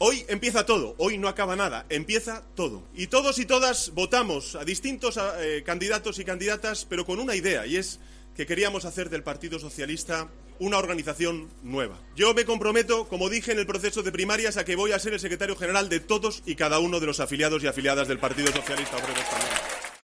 "Todos tenemos que reconocer hoy que quien ha ganado ha sido el PSOE y cuando gana el PSOE gana España", ha proclamado en su primera comparecencia pública tras conocerse los resultados de las primarias, ante varias decenas de militantes y simpatizantes socialistas, que le han abucheado cuando ha mencionado a Susana Díaz y a Patxi López.
En medio de esos abucheos, él ha pedido tranquilidad: "Hay que hacerlo", ha señalado para justificar que era necesario el agradecimiento a las otras dos candidaturas.